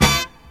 horn_hit1.wav